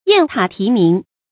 雁塔題名 注音： ㄧㄢˋ ㄊㄚˇ ㄊㄧˊ ㄇㄧㄥˊ 讀音讀法： 意思解釋： 舊時考中進士的代稱。